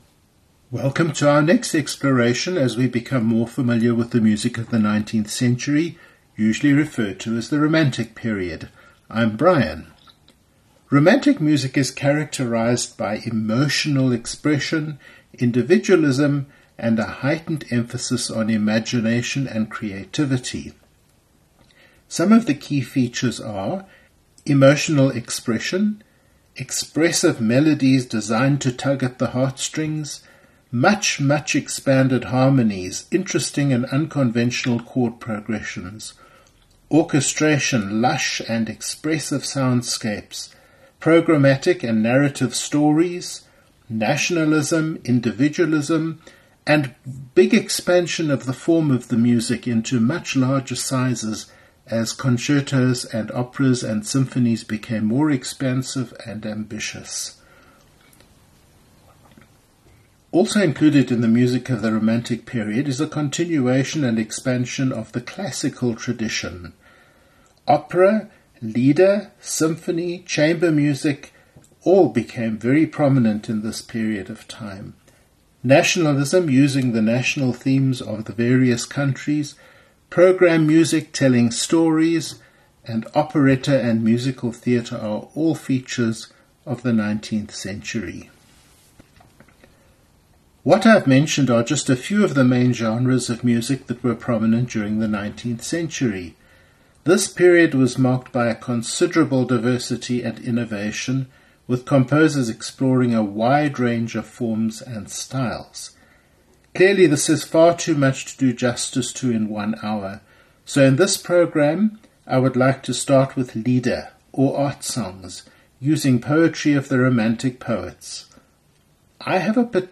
Programme 46: about Music- The Art of Listening: Romantic Lieder - Gay SA Radio
We are starting with fingerprints of the different genres of the 19th century, and first up is Lieder, or German songs.